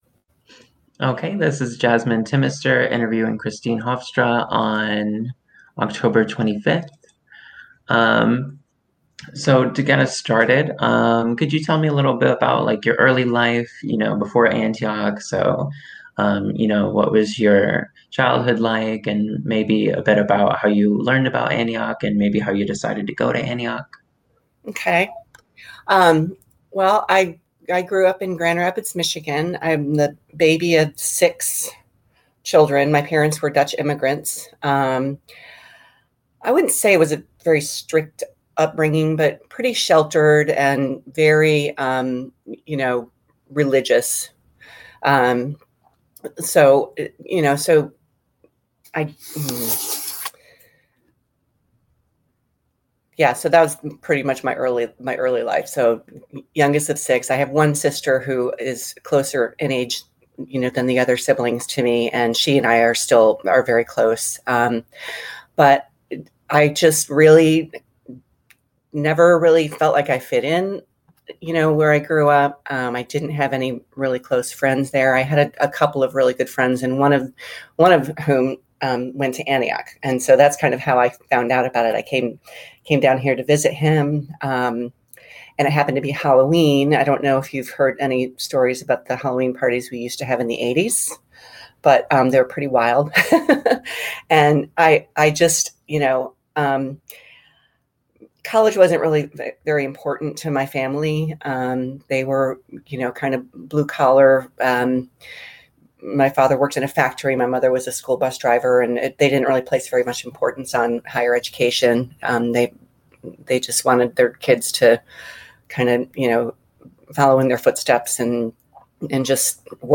Oral History in the Liberal Arts | LGBTQ+ Experiences at Antioch College